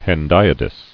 [hen·di·a·dys]